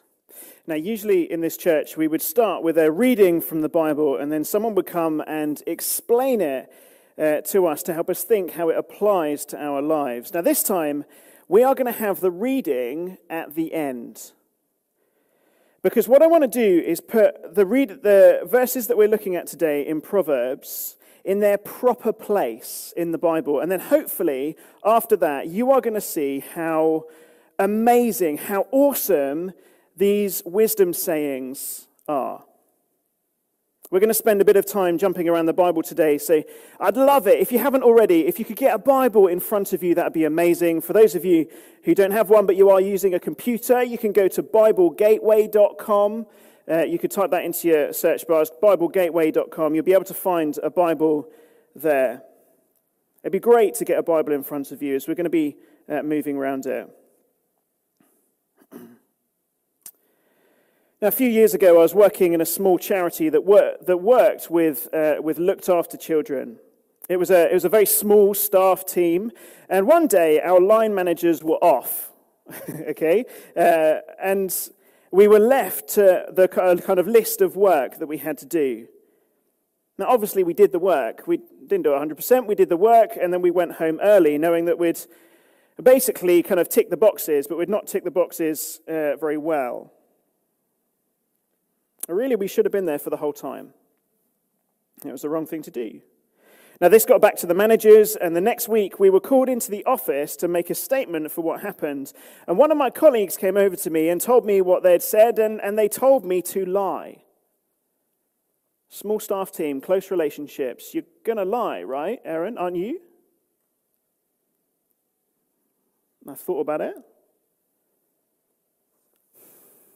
A sermon preached on 15th November, 2020, as part of our Proverbs series.